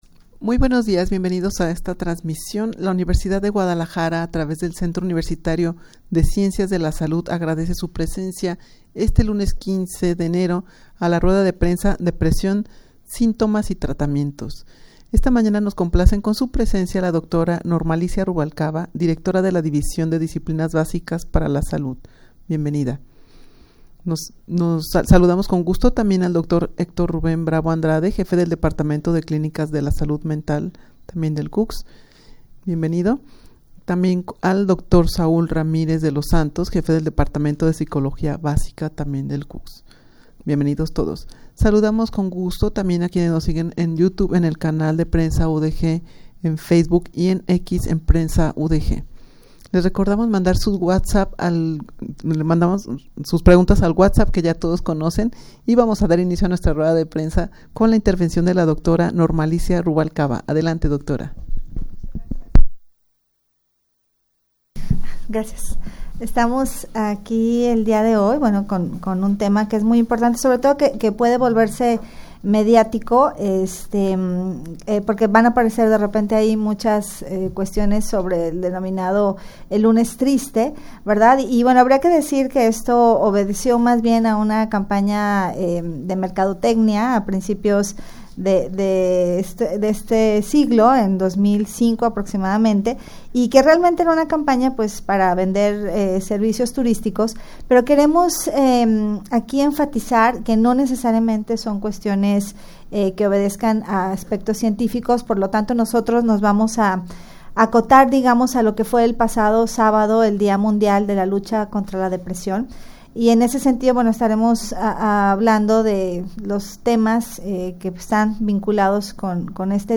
Audio de la Rueda de Prensa
rueda-de-prensa-22dia-mundial-de-la-lucha-contra-la-depresion.mp3